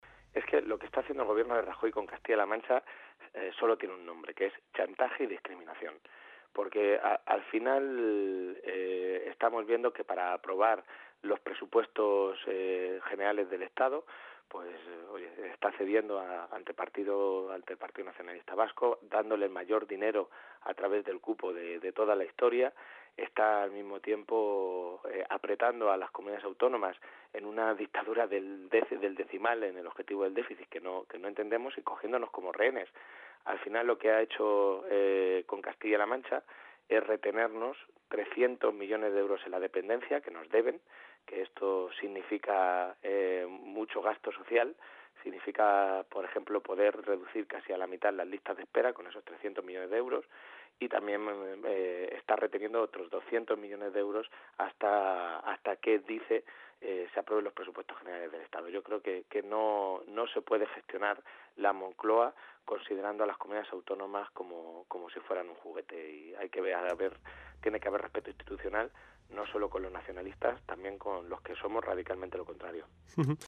Toledo, 15 de enero de 2018.- El secretario de Organización del PSOE de Castilla-La Mancha, Sergio Gutiérrez, en una entrevista concedida a la Cadena Cope en nuestra región, ha asegurado que lo que está haciendo el gobierno de Rajoy con Castilla-La Mancha solo tiene un nombre: “Chantaje y discriminación”.
Cortes de audio de la rueda de prensa